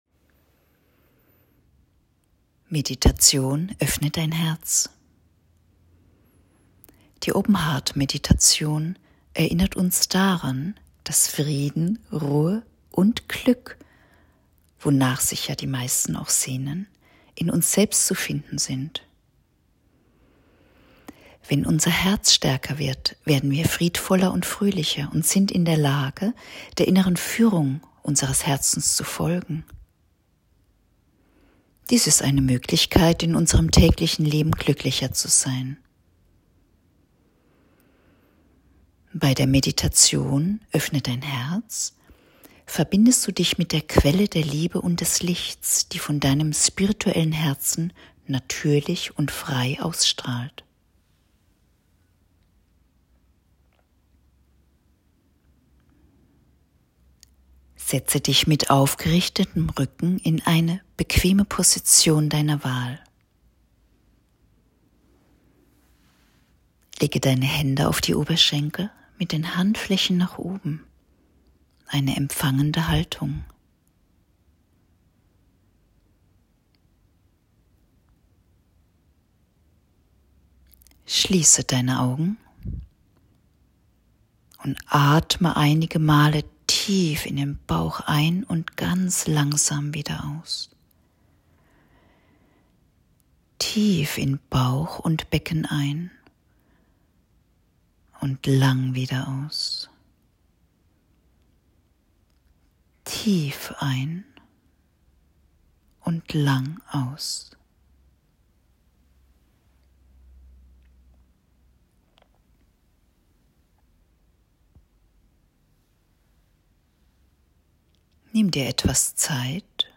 Meditationsanleitung_Oeffne-dein-Herz_YogaInspiration5.m4a